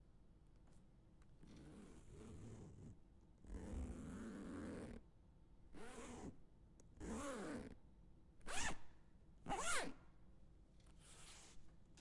机关枪 " 马克西姆俄罗斯机关枪遥遥领先
用H4N的4轨模式录制，用MKH60和内部麦克风进行延迟和混响。